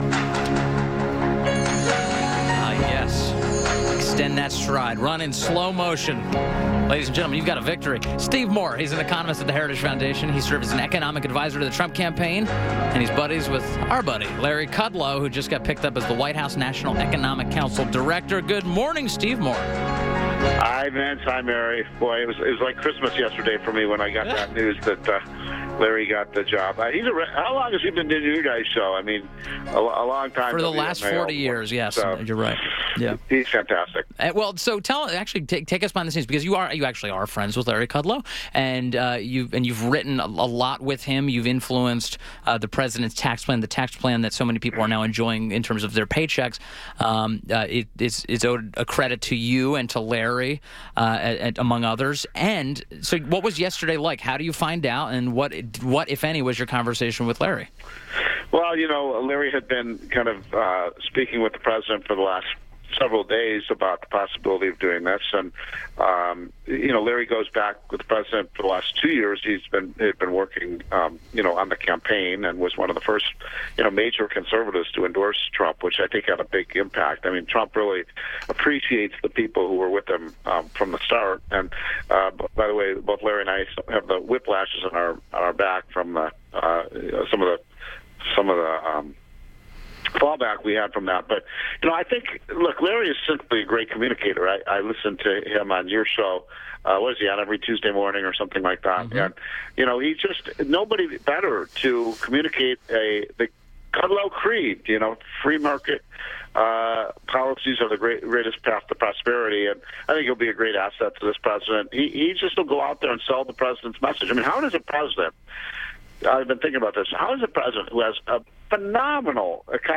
WMAL Interview - STEVE MOORE - 03.15.18
INTERVIEW - STEVE MOORE - Economist at The Heritage Foundation and served as an economic adviser to the Trump campaign – discussed Larry Kudlow replacing Gary Cohn as White House National Economic Council Director